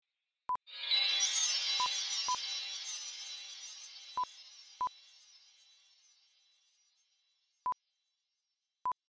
Sound Design Chatter Glass Shatter 2 (sound FX)
Sound design, glass shatter. Magic Sparkle. Twinkle. Science Fiction Sound Effects, Magic Sounds. 16 Bit 48 kHz Stereo WAV
SoundDesign_Chatter_GlassShatter_2_plip.mp3